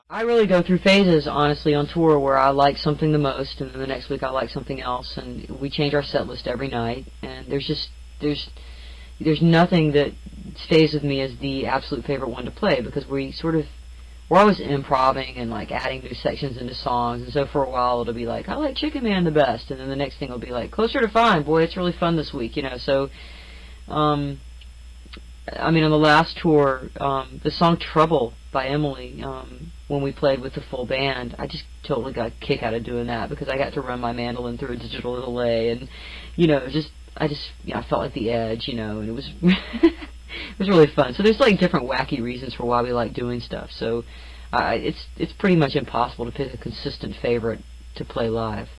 200xxxxxa-01-interview.wav